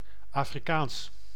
Ääntäminen
Ääntäminen : IPA: [afriˈkaːns] Tuntematon aksentti: IPA: /ˈafʁiˌkaːns/ IPA: /ˌafʁiˈkaːns/ Haettu sana löytyi näillä lähdekielillä: saksa Käännös Ääninäyte 1. Afrikaans {n} BE Artikkeli: das .